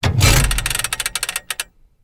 controlStick2.wav